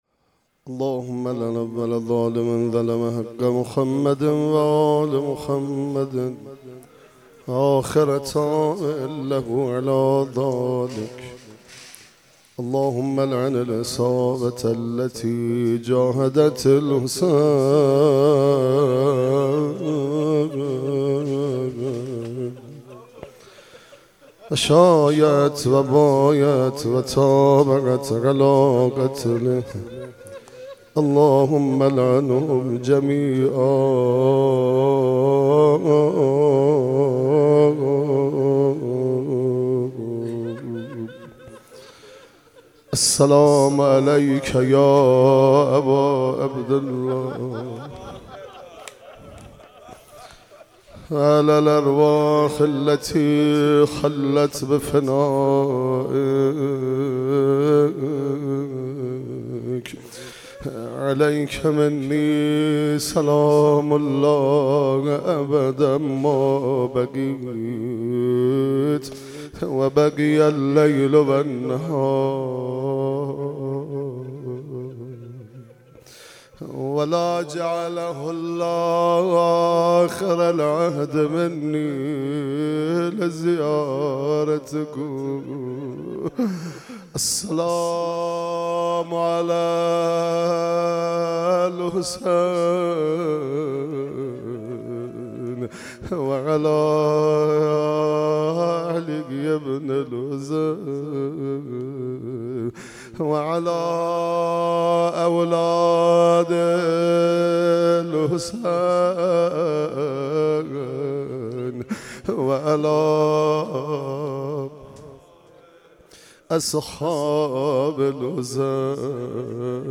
روضه امام حسین علیه السلام